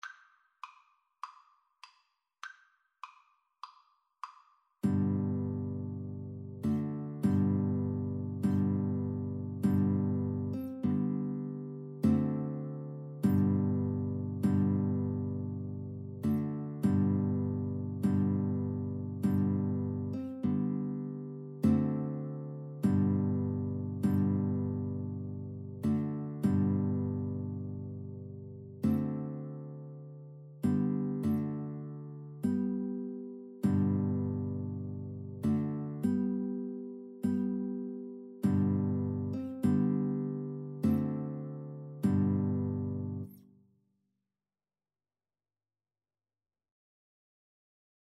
ClarinetGuitar
4/4 (View more 4/4 Music)